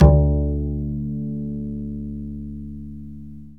DBL BASS EN2.wav